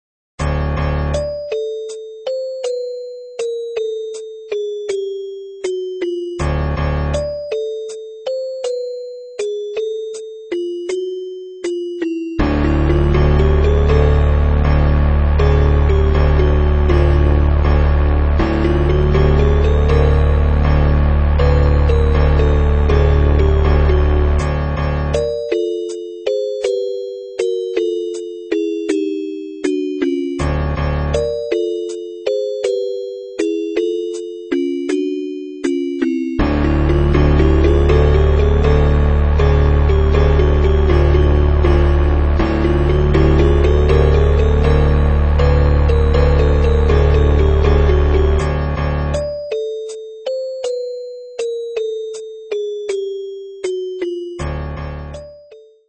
Dm